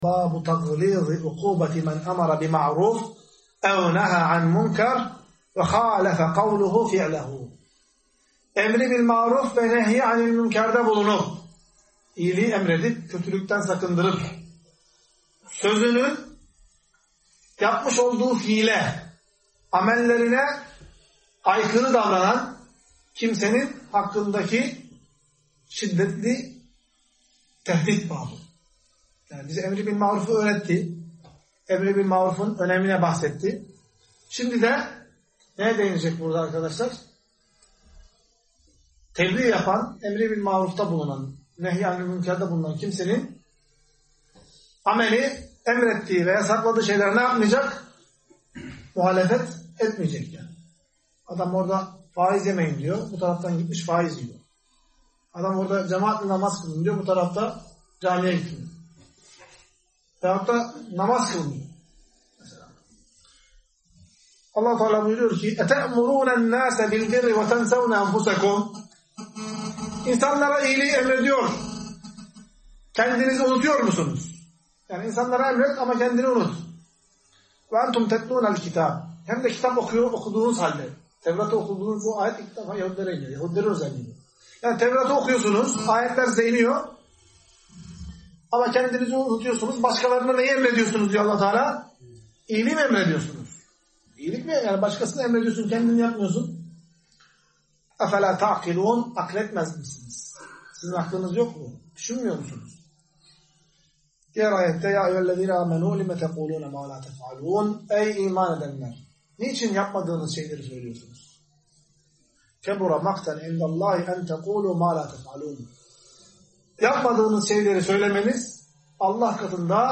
Ders.mp3